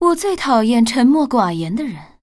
文件 文件历史 文件用途 全域文件用途 Dana_tk_02.ogg （Ogg Vorbis声音文件，长度2.2秒，106 kbps，文件大小：29 KB） 源地址:游戏语音 文件历史 点击某个日期/时间查看对应时刻的文件。